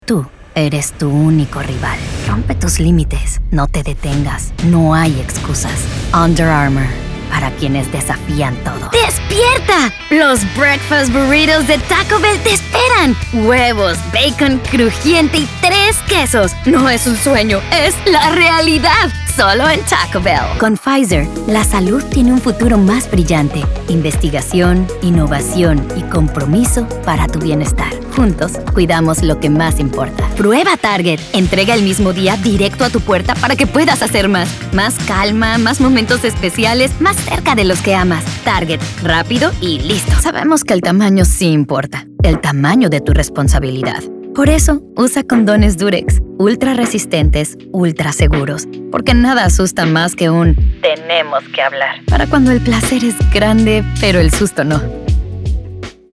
Commercial Version 1
Mexican